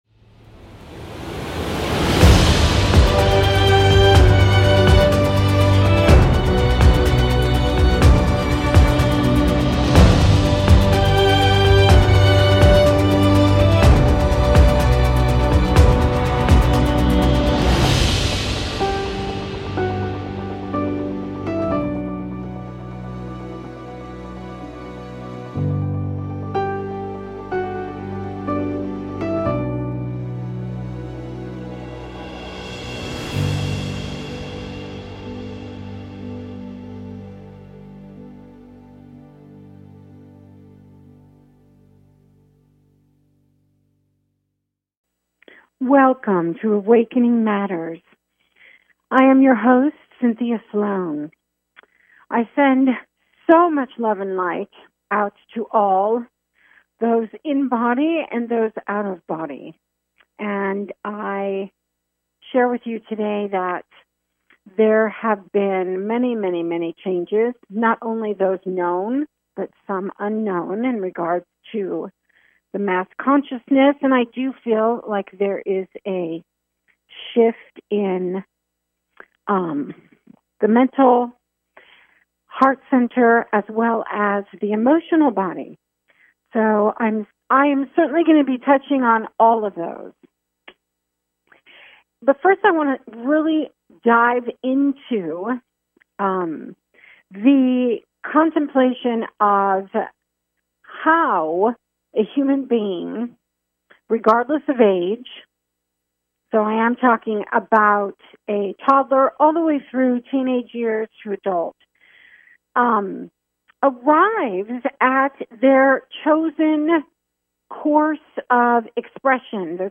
A spiritual dialogue that invites divine wisdom, joy and laughter.